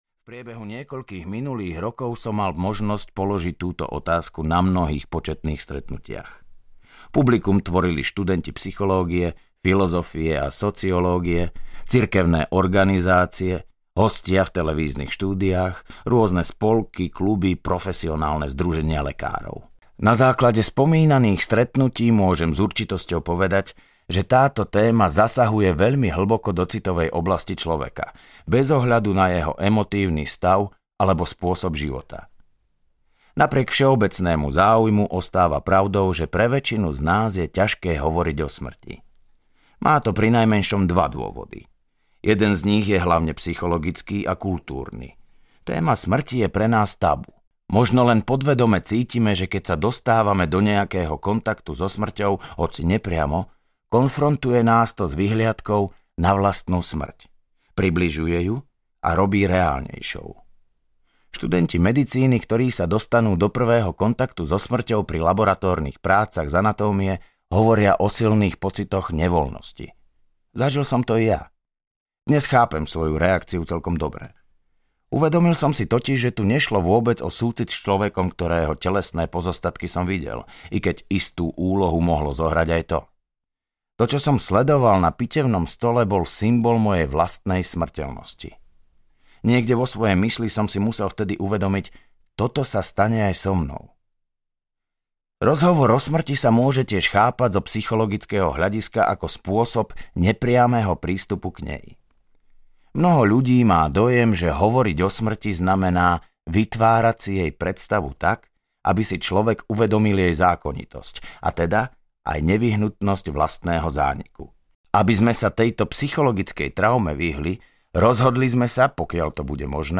Život po živote audiokniha
Ukázka z knihy